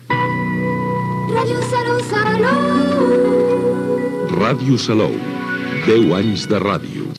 Indicatiu dels 10 anys de l'emissora